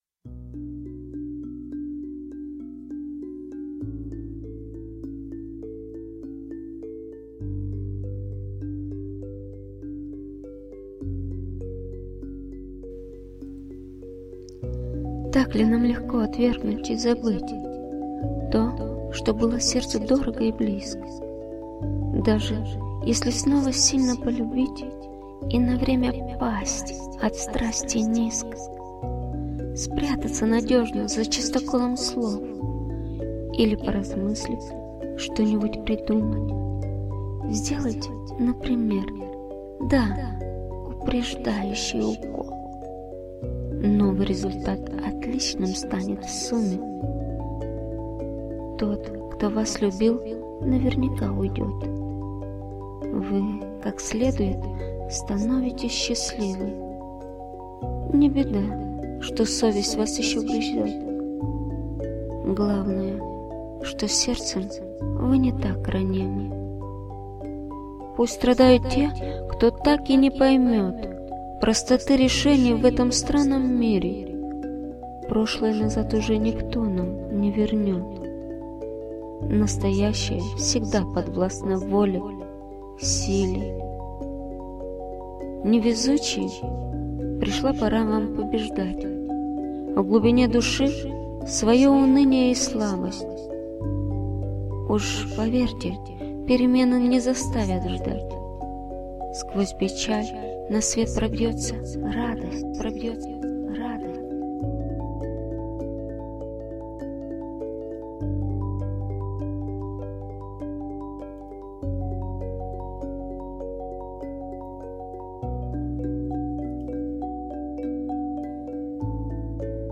ТИП: Музика
СТИЛЬОВІ ЖАНРИ: Ліричний
ВИД ТВОРУ: Пісня